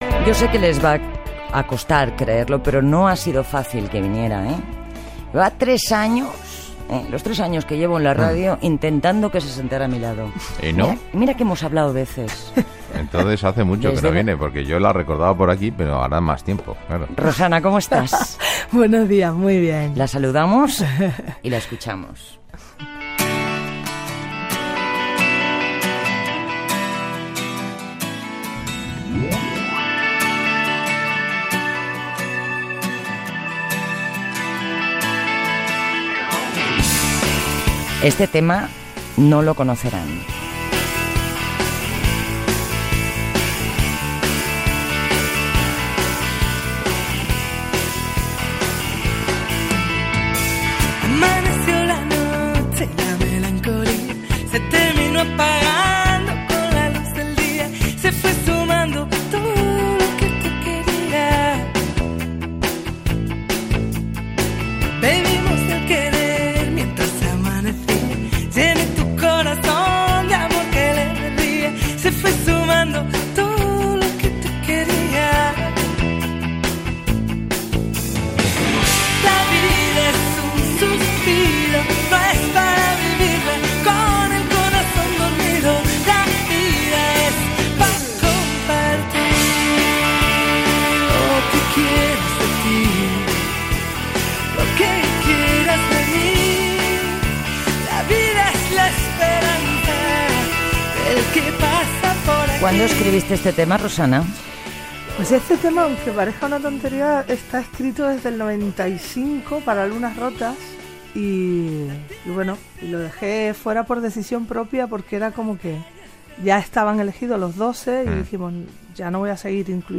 Entrevista a la cantant Rosana (Rosana Arbelo ) amb motiu dels 10 anys de la publicació del disc "Lunas rotas" Gènere radiofònic Info-entreteniment